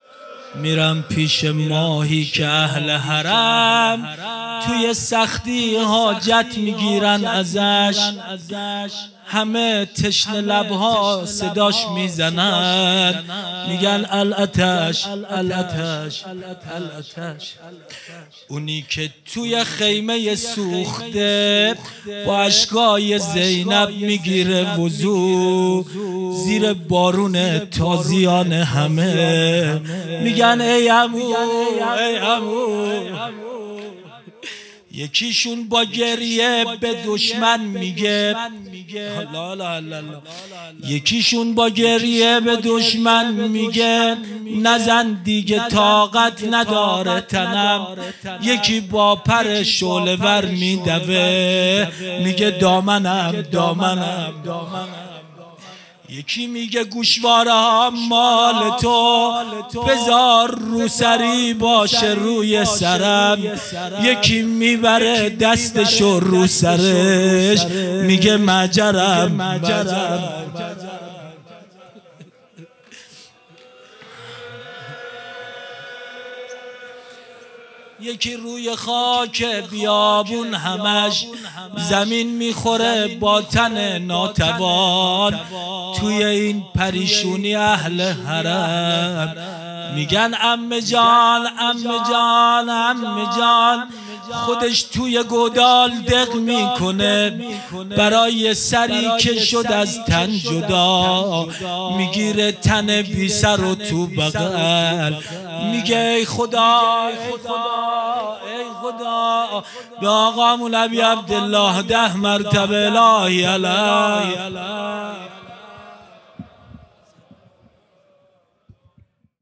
روضه پایانی
مــراسـم احیــاء شــب بیـــست و ســـوم مـاه مــبارک رمــضان ســال ۱۴۴۶